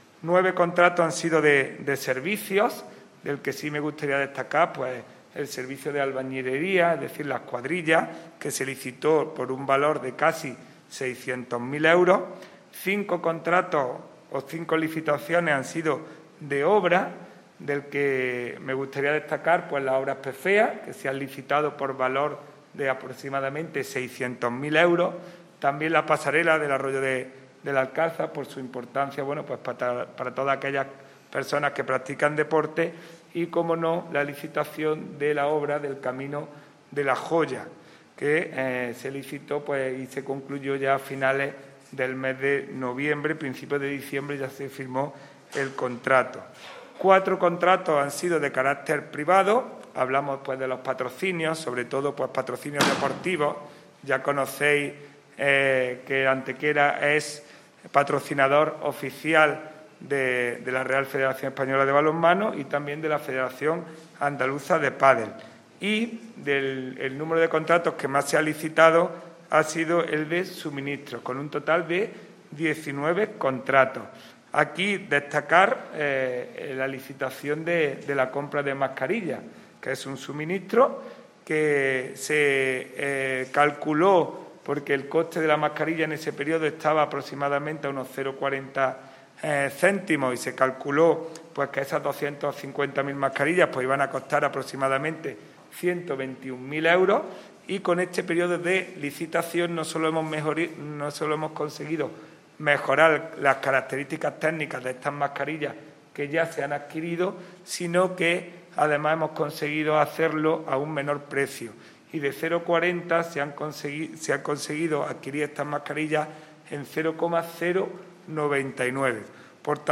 ENLACE A VÍDEO DE LA RUEDA DE PRENSA EN YOUTUBE
Cortes de voz